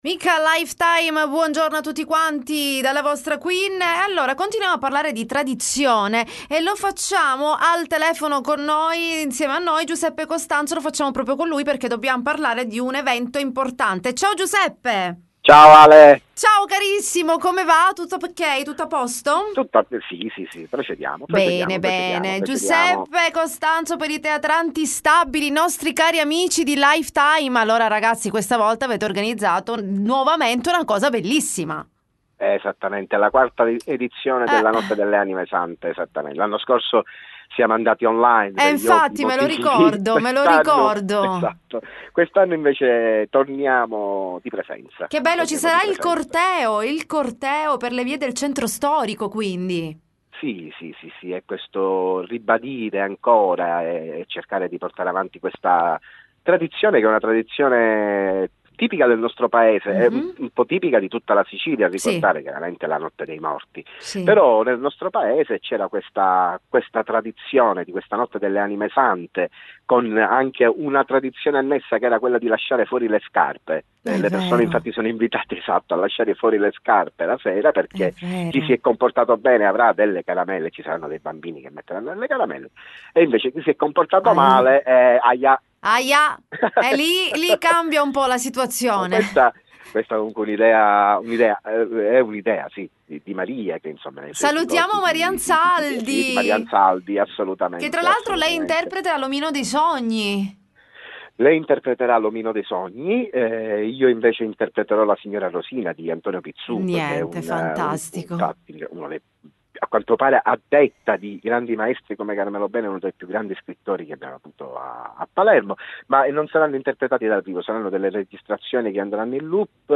L.T. Intervista Teatranti Instabili